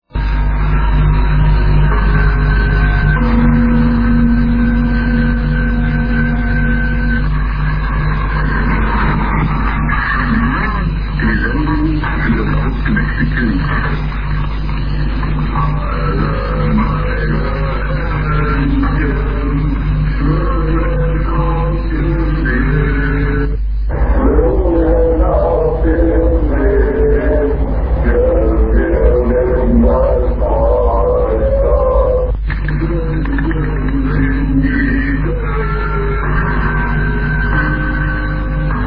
Начало » Записи » Номерные станции
Хор мужских голосов поёт шуточную песенку "Alle meine Entchen".
g3_stasi_last_transmission.mp3